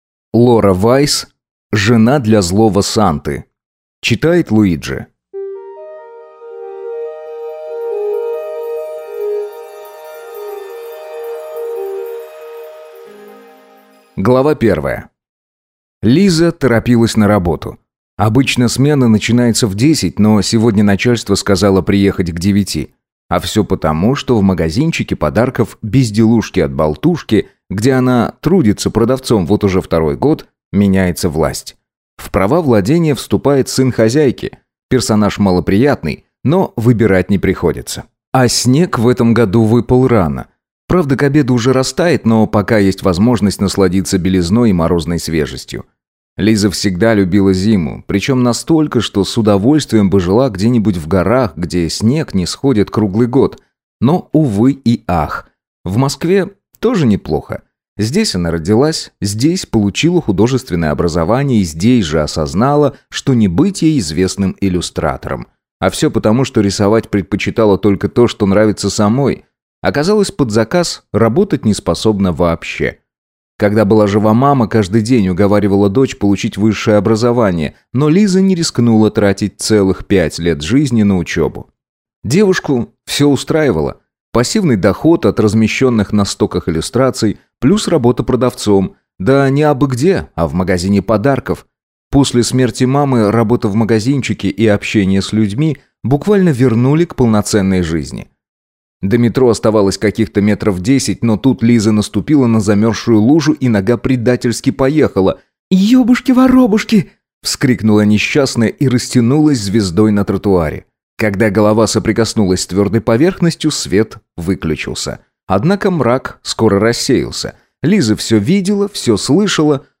Аудиокнига Жена для злого Санты | Библиотека аудиокниг
Прослушать и бесплатно скачать фрагмент аудиокниги